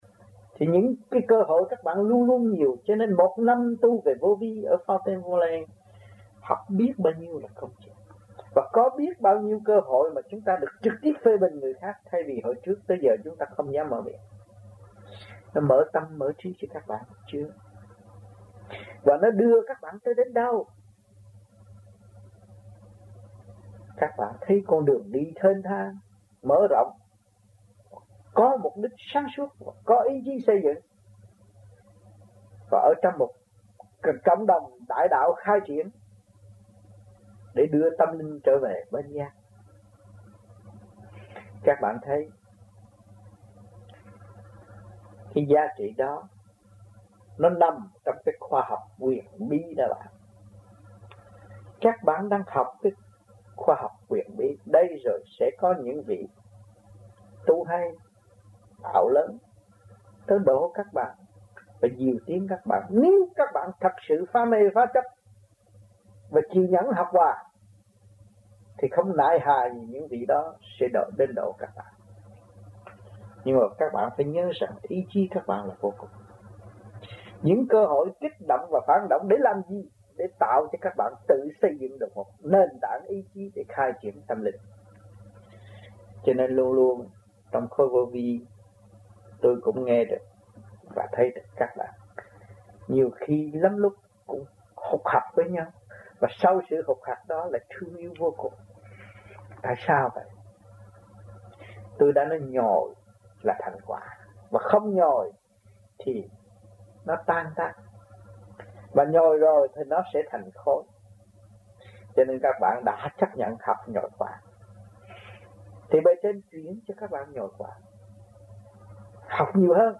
Giảng bởi : Thiền Sư
Canada Trong dịp : Sinh hoạt thiền đường >> wide display >> Downloads